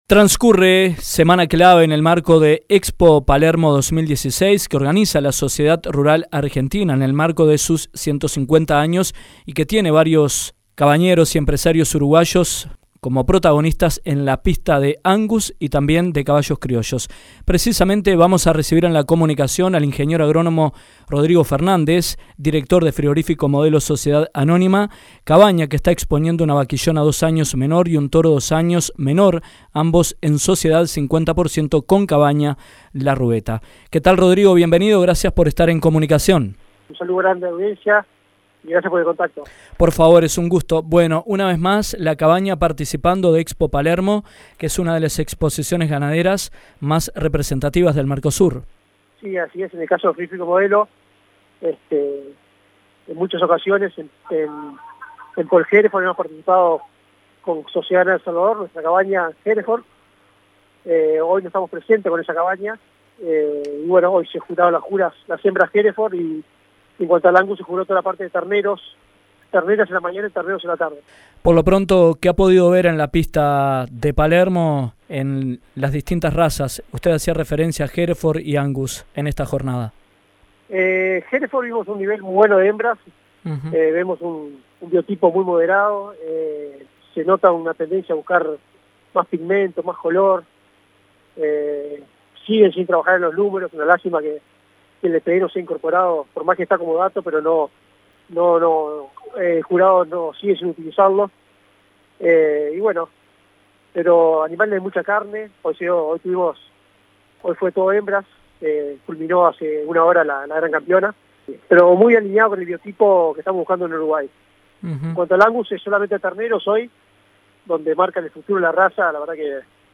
Transcurre semana clave para Expo Palermo que es organizada por la Sociedad Rural Argentina en el marco de sus 150 años, teniendo a varios uruguayos como protagonistas en la pista de una de las exposiciones más representativas del Mercosur. En entrevista con Dinámica Rural